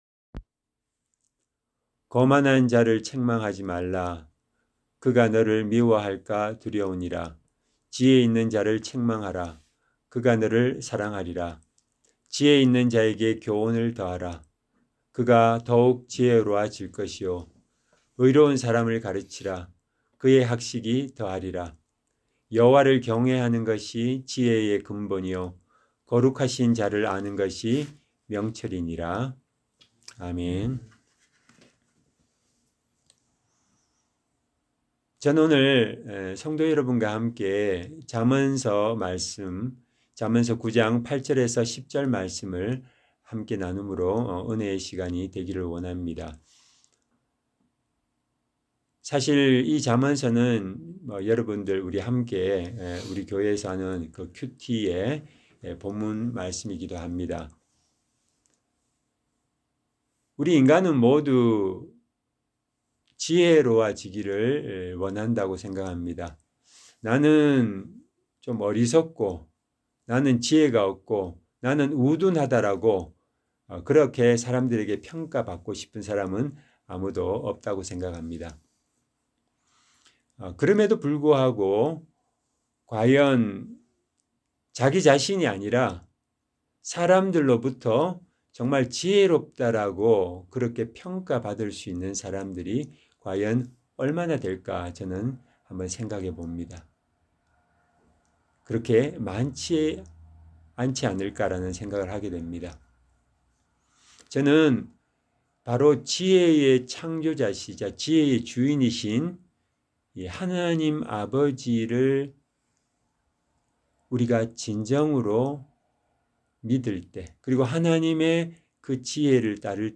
Service Type: 주일 예배